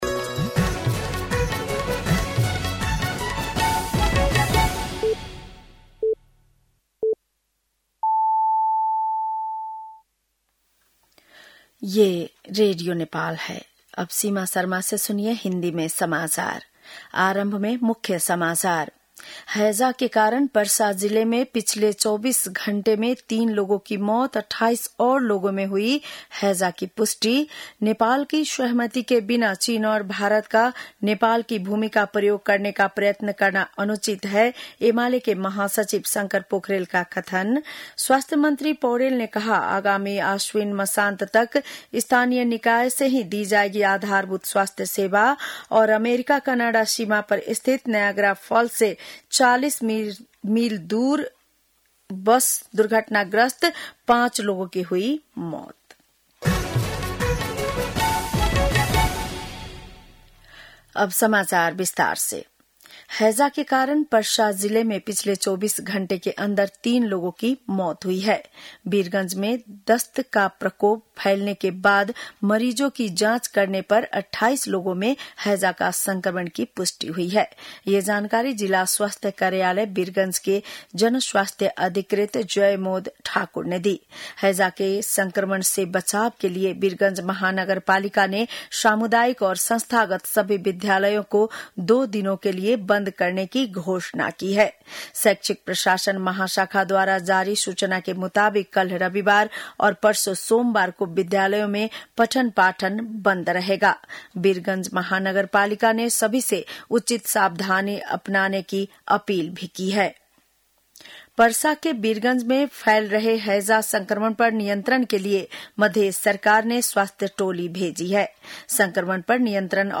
बेलुकी १० बजेको हिन्दी समाचार : ७ भदौ , २०८२